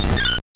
Cri de Tournegrin dans Pokémon Or et Argent.